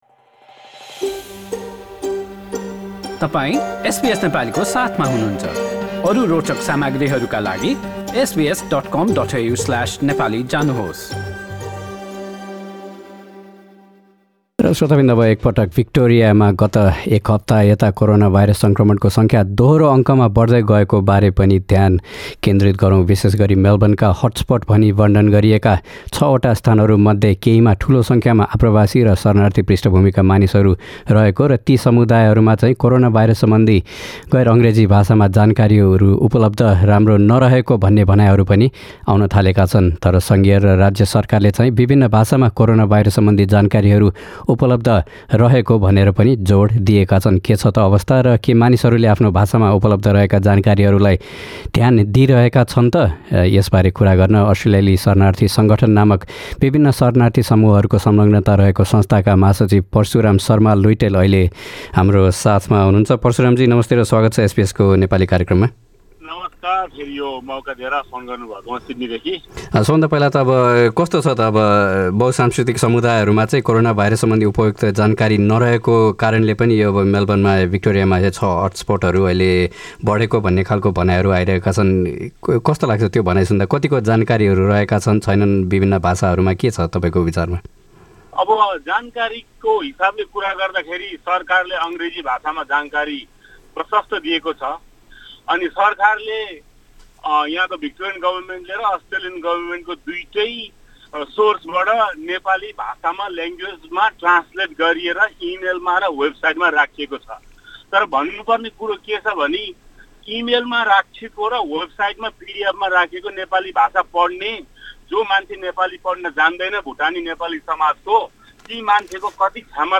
यसबारे गरिएको कुराकानी।